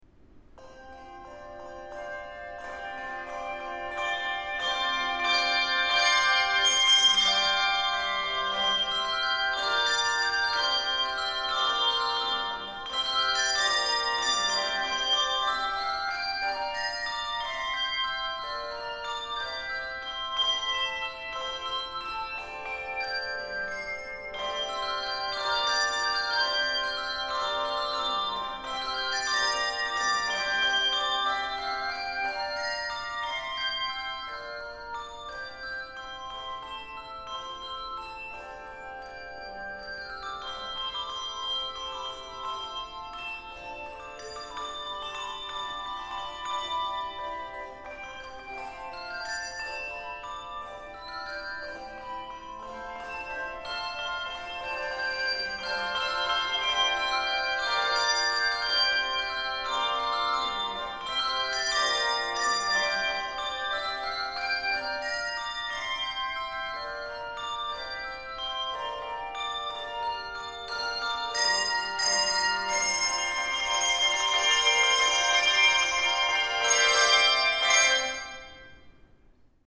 Voicing: Handbells 3-4 Octave